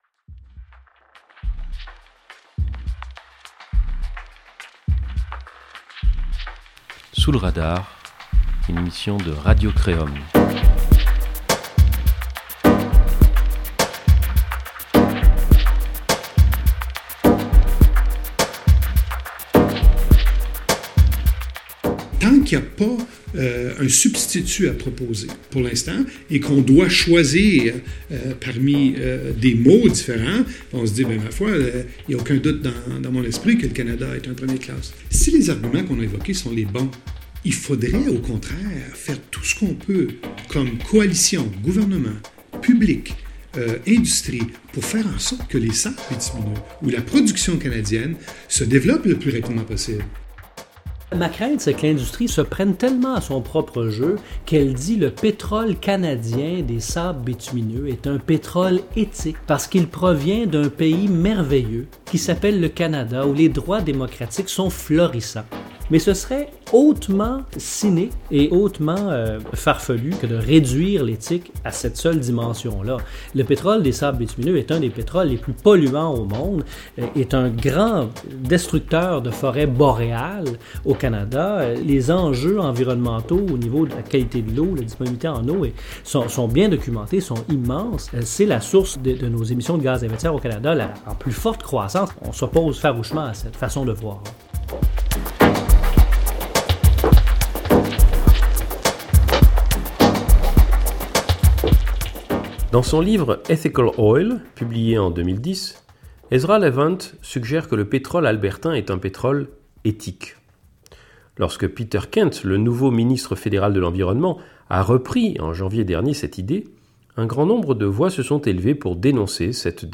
Suivez Sous le radar, l’émission webradio de Radio-Créum qui interroge les aspects insoupçonnés de l’actualité.